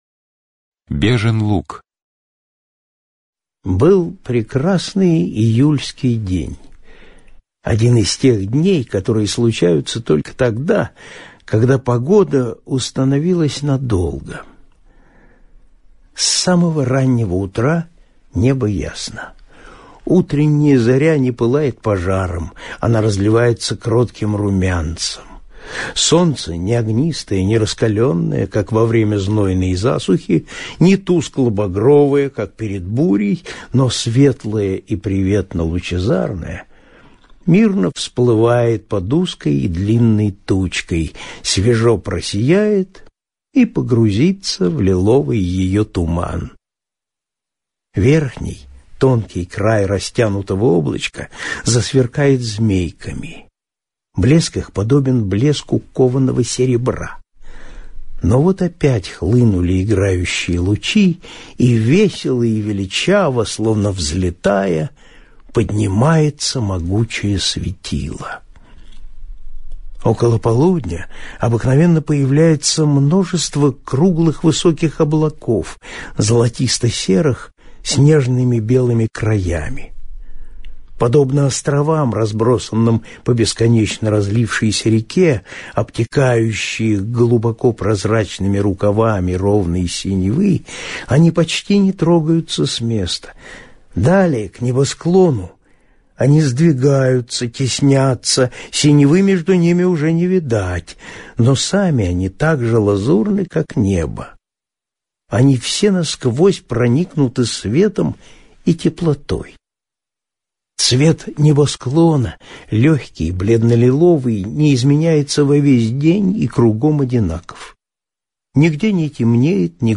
Бежин луг - аудио рассказ Тургенева И.С. Рассказ о том, как автор охотился и заблудился.